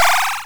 apple_powerup.wav